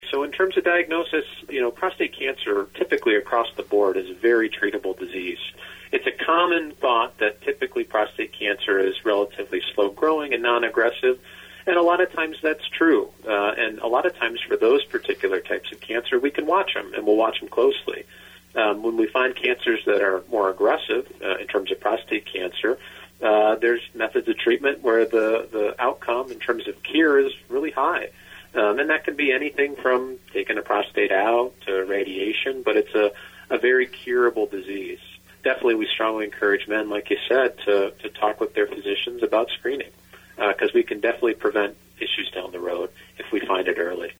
Adrian, MI – November is Men’s Health Month, and WLEN spoke with a medical professional from the region about different important aspects of the topic.